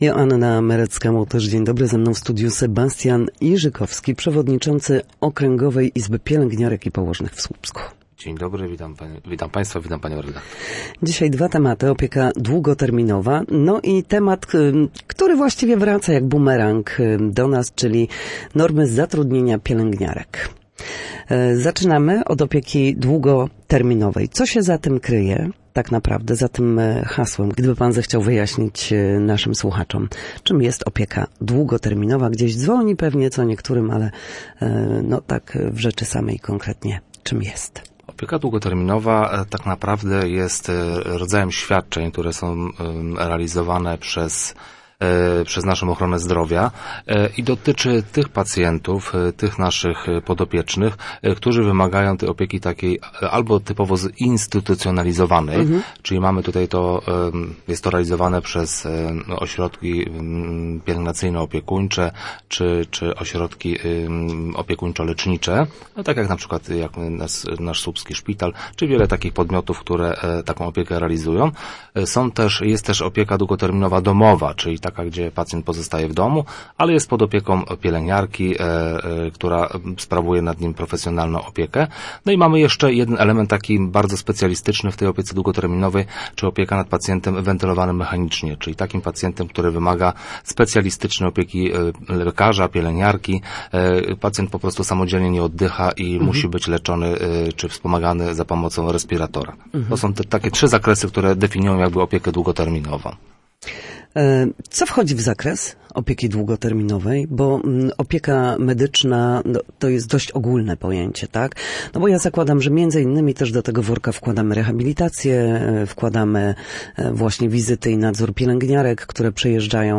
W każdą środę, w popołudniowym Studiu Słupsk Radia Gdańsk, rozmawiamy o tym, jak wrócić do formy po chorobach i urazach.